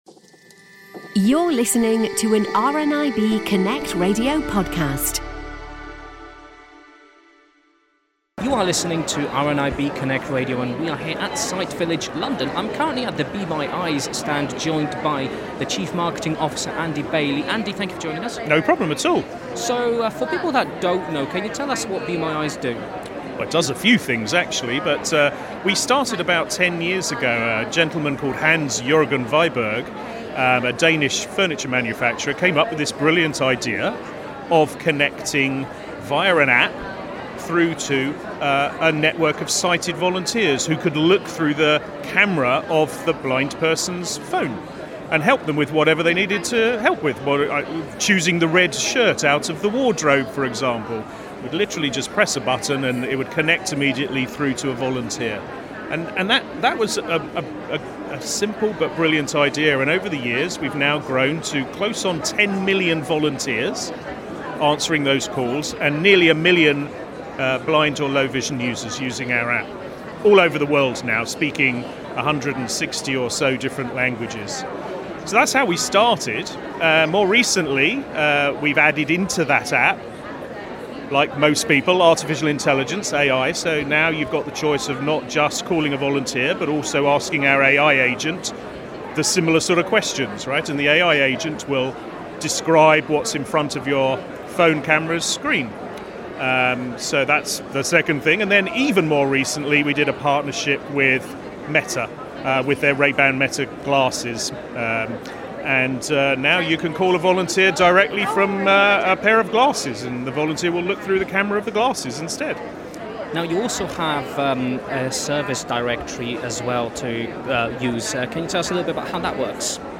Be My Eyes At Sight Village London 2025